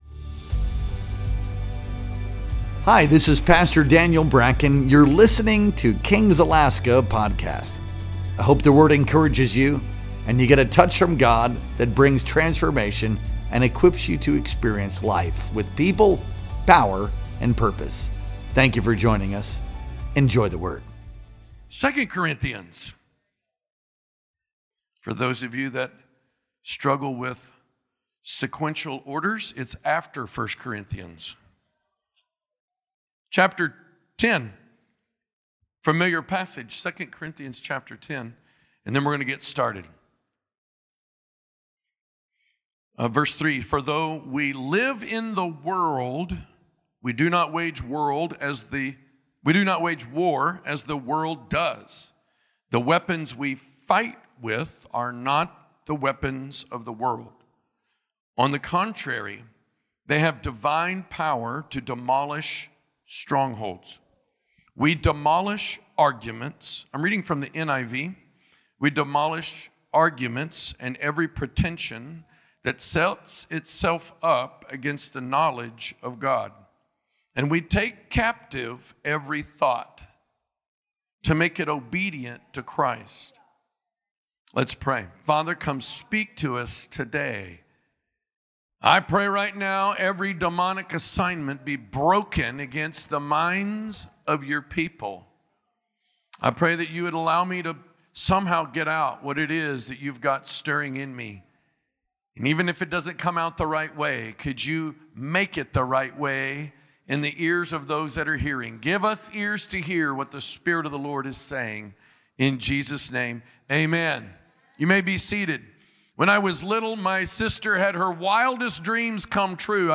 Our Wednesday Night Worship Experience streamed live on October 29th, 2025.